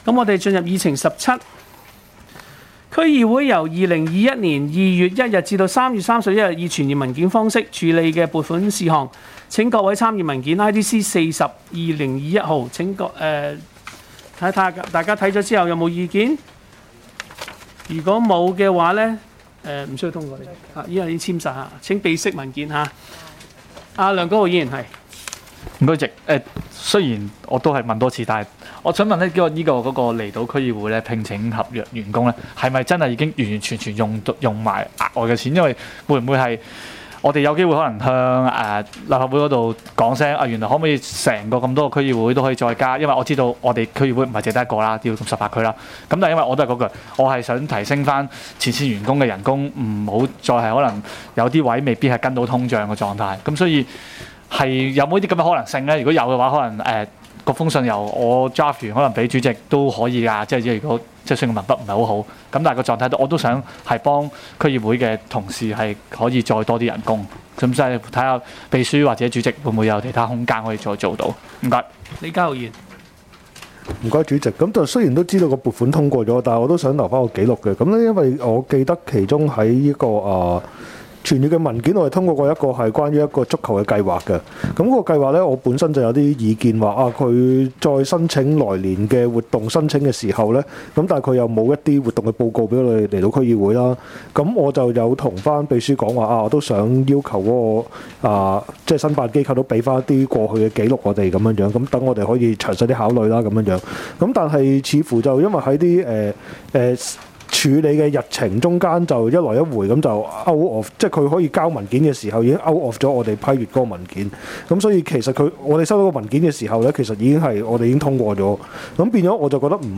區議會大會的錄音記錄
離島區議會第二次會議
離島區議會會議室